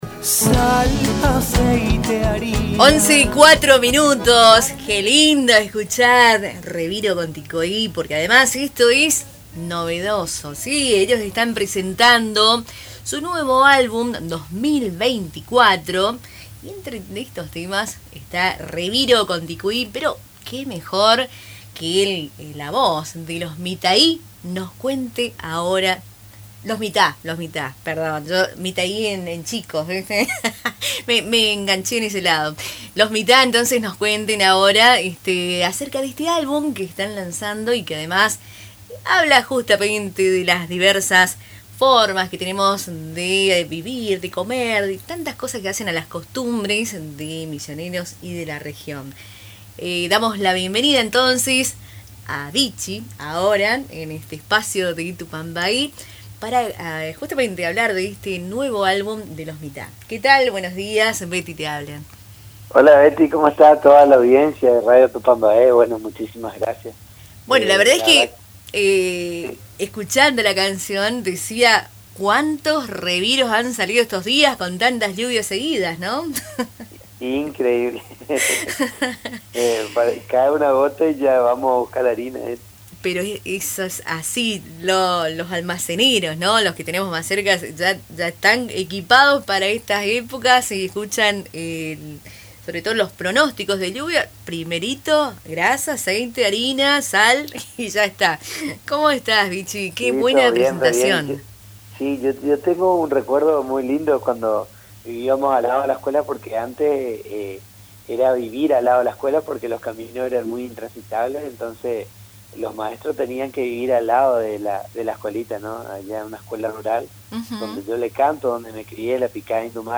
en una entrevista con Radio Tupa Mbae